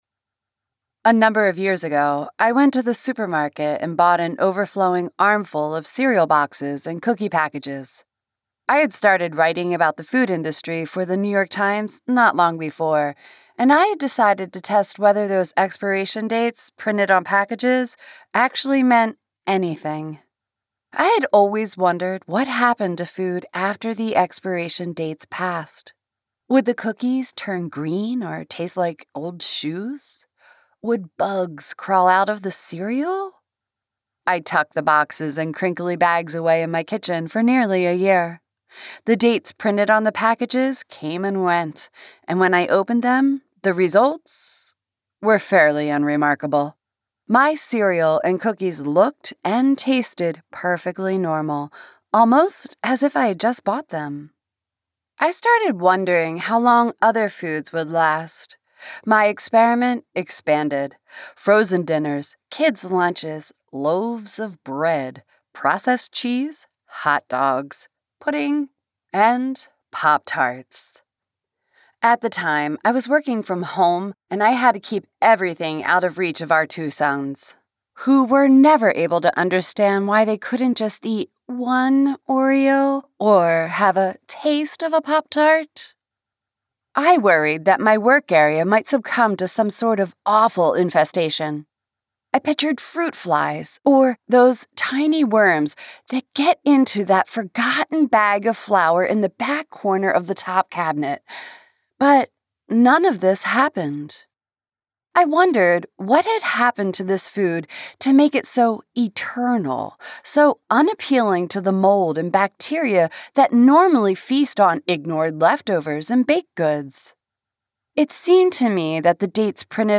Narrator
Accent Capabilities: All American accents. Also British, Russian, and Israeli.